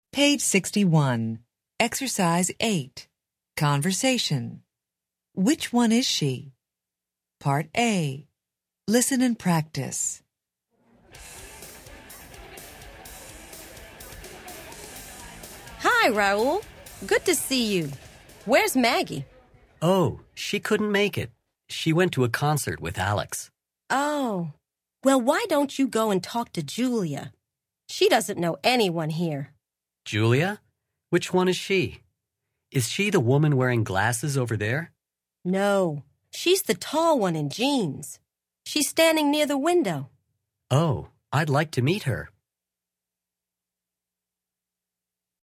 American English
Interchange Third Edition Level 1 Unit 9 Ex 8 Conversation Track 27 Students Book Student Arcade Self Study Audio